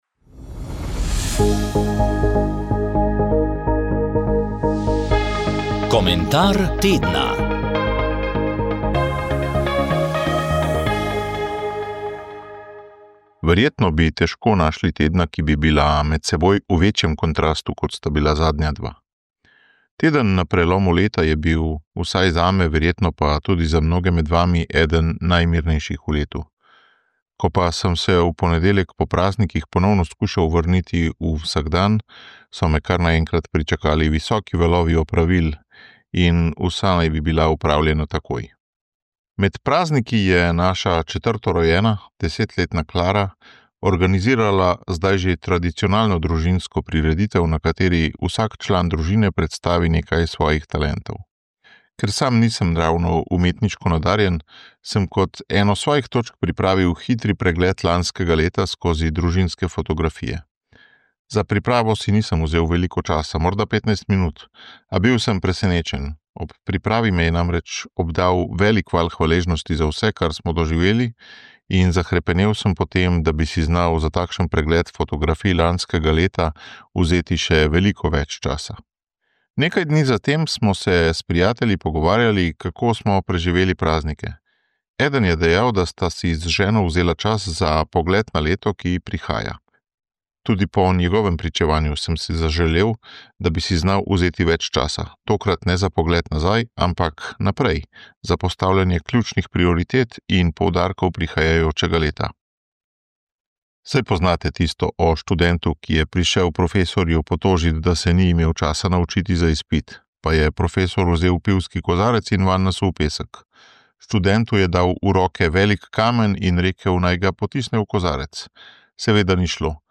V Kranju, v Prešernovi hiši so včeraj odprli razstavo Andrej Šifrer 70 let, Nič proti VEČnosti. Mi smo bili tam, poklepetali z Andrejem in kustosinjama.